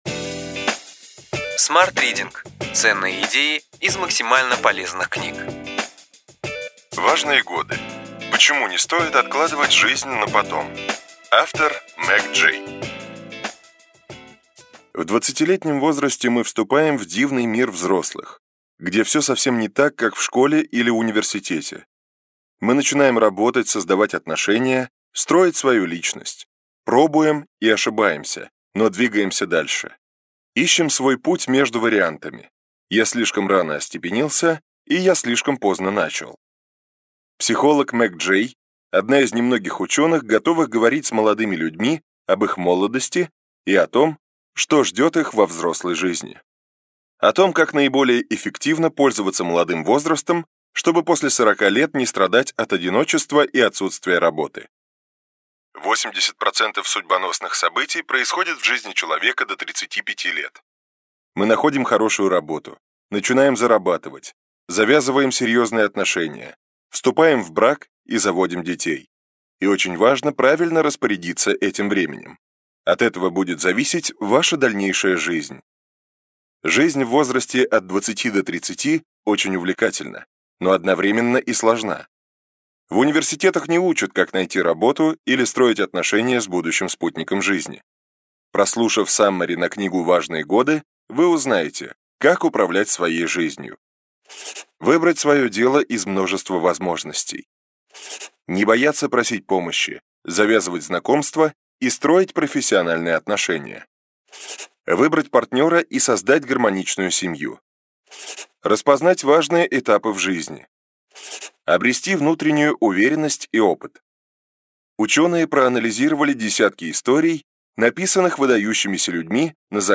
Аудиокнига Ключевые идеи книги: Важные годы. Почему не стоит откладывать жизнь на потом.